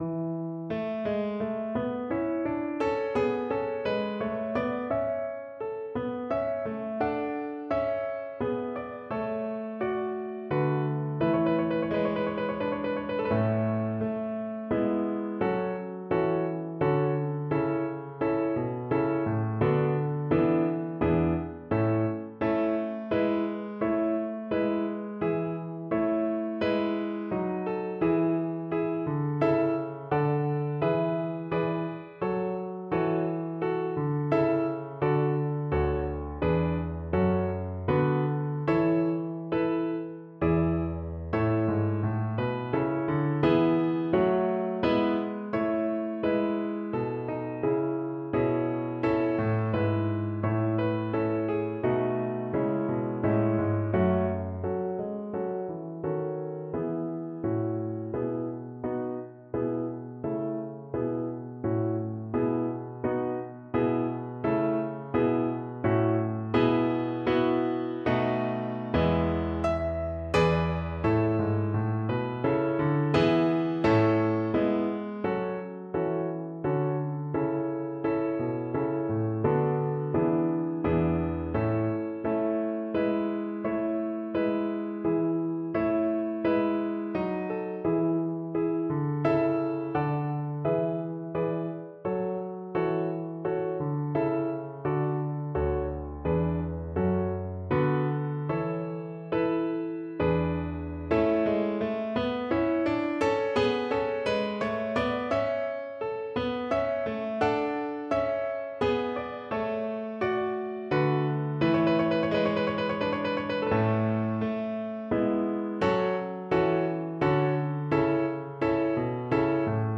A major (Sounding Pitch) (View more A major Music for Violin )